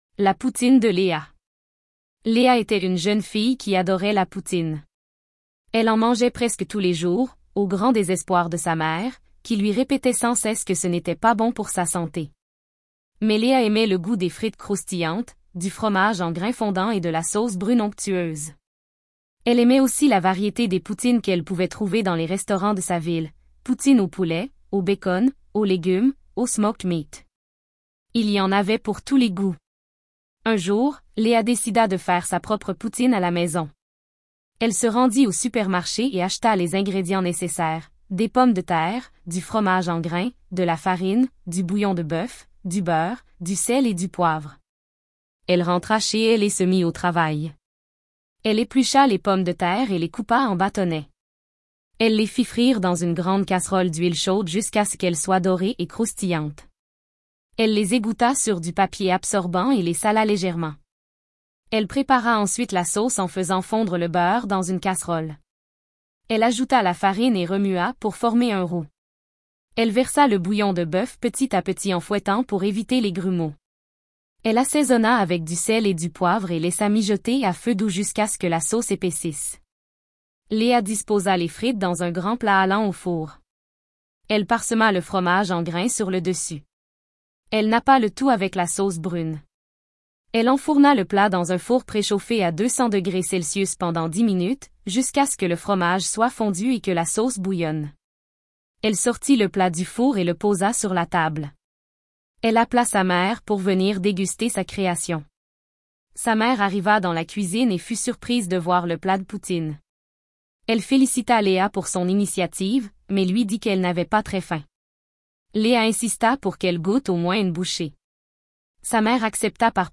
La poutine - courte histoire IA